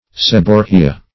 Seborrhea \Seb"or*rhe*a\, n. [NL., fr. L. sebum tallow + Gr.